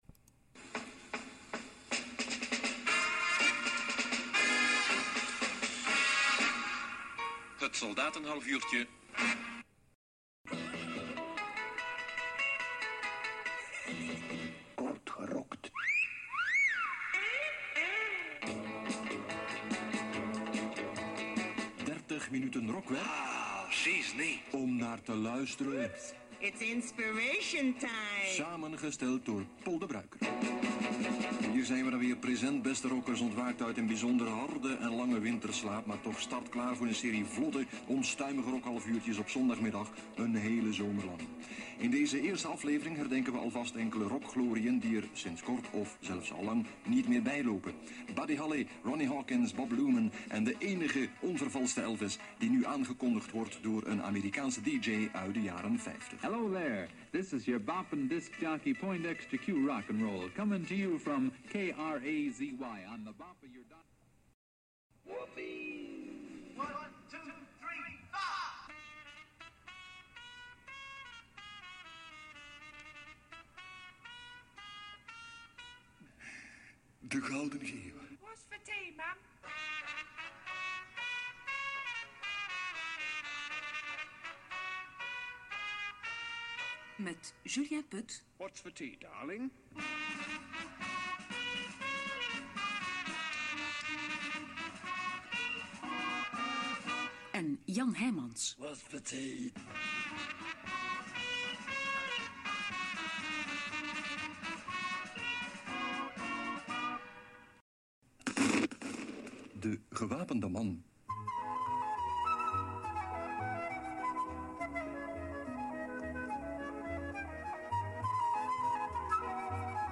In deze compilatie hoor je tunes van vijf de programma's